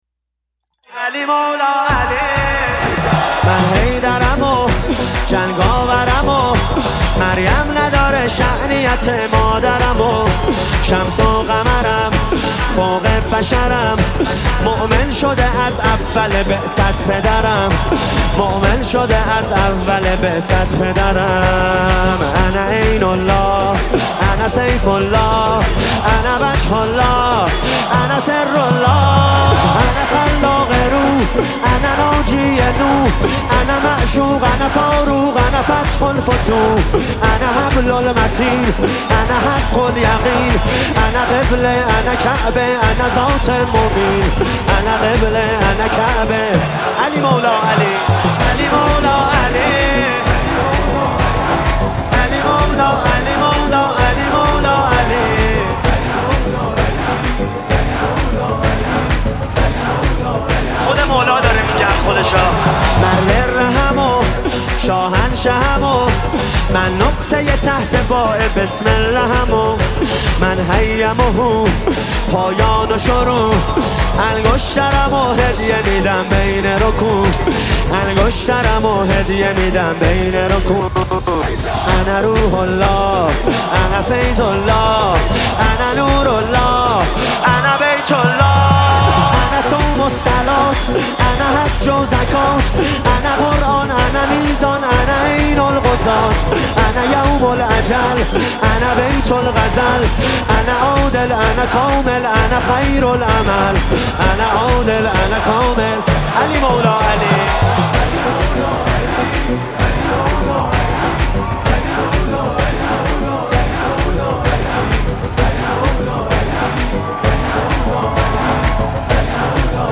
دانلود آهنگ عید غدیر شاد ۱۴۰۲ + اهنگ عید غدیر با دف